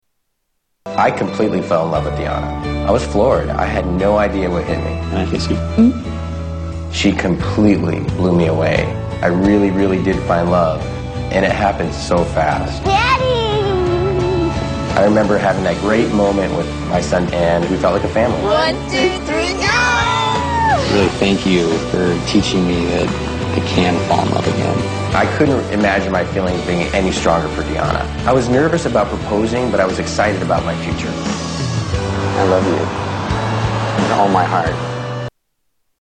Tags: Television Jason Mesnick The Bachelor Jason Mesnick Clips Jason Mesnick Interviews